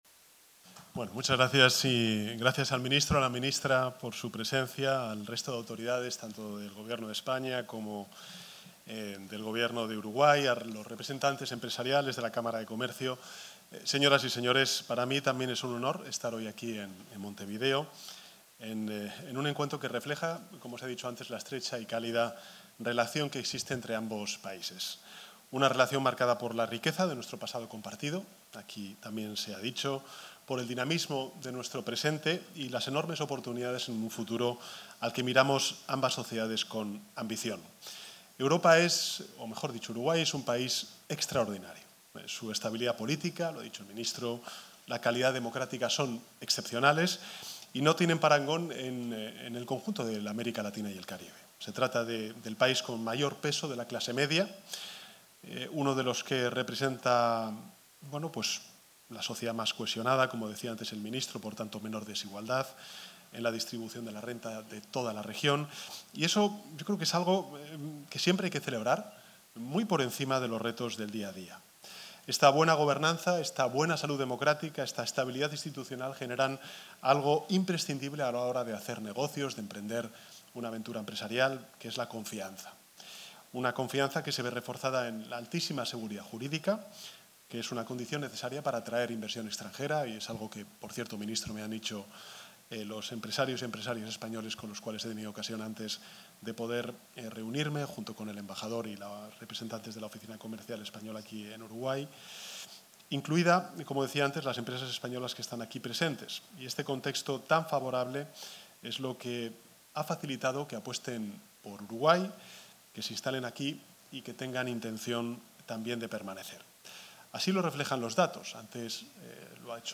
Palabras del presidente de España, Pedro Sánchez 22/07/2025 Compartir Facebook X Copiar enlace WhatsApp LinkedIn El presidente del Gobierno de España, Pedro Sánchez, expuso ante empresarios de ese país y el nuestro en el hotel Radisson, en el marco de su visita a Uruguay.